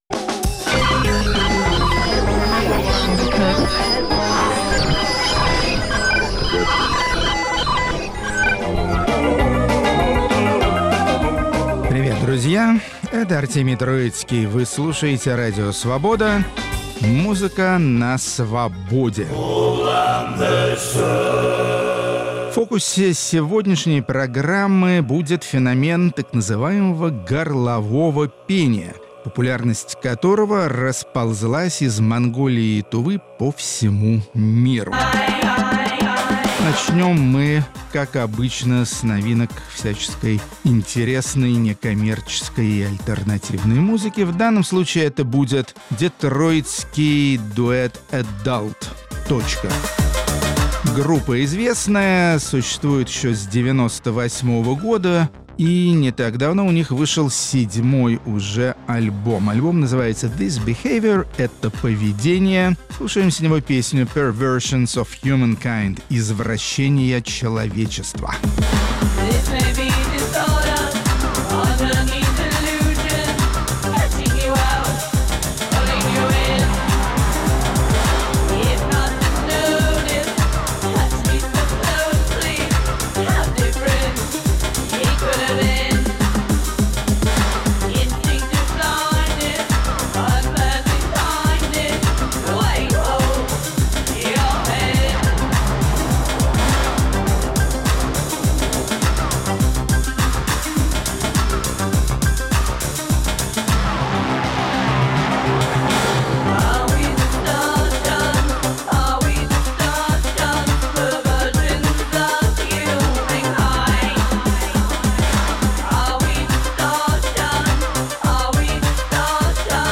Музыка на Свободе. 19 апреля, 2020 Исполнители из разных стран и континентов, работающие в технике горлового пения. Рок-критик Артемий Троицкий свидетельствует, что эти протяжные песни завоёвывают всё новые и новые творческие пространства.